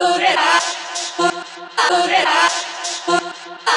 • chopped vocals ping pong house delayed (8).wav
chopped_vocals_ping_pong_house_delayed_(8)_Avy.wav